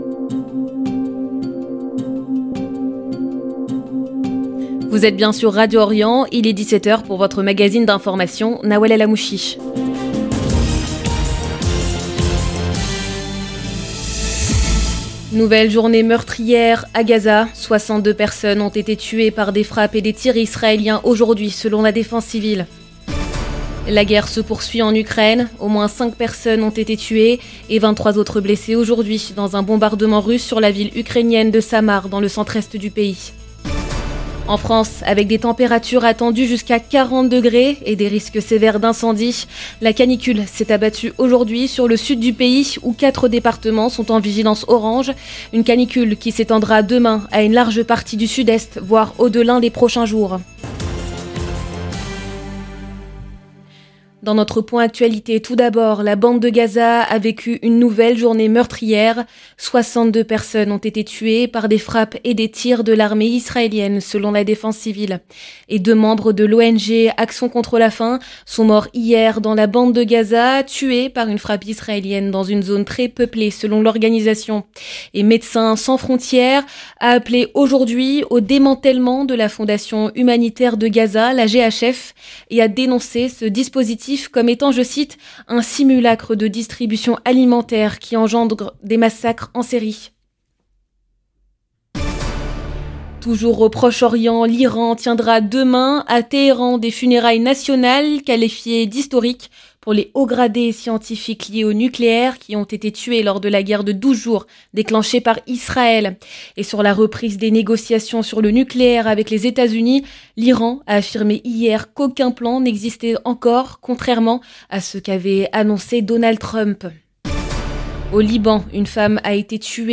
Magazine d'information de 17H du 27 juin 2025